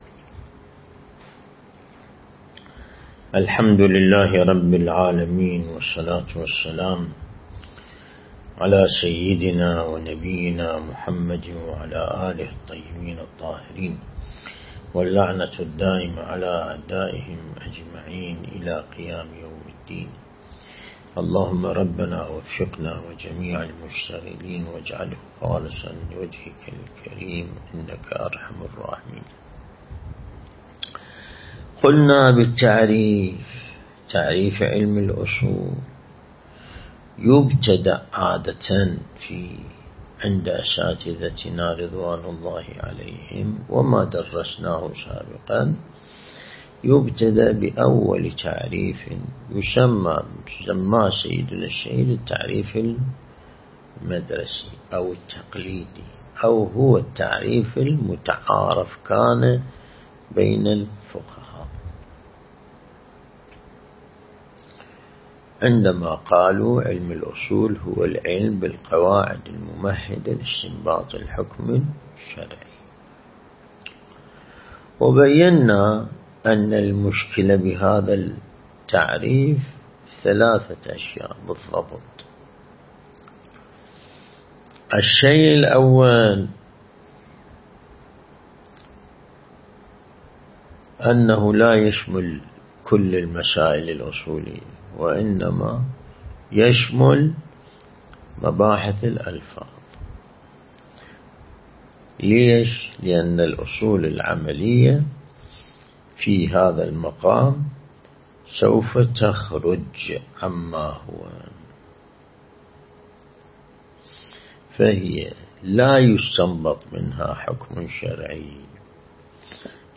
درس البحث الخارج الأصول (5)
النجف الأشرف موضوع دروس بحث خارج اصول Copied to clipboard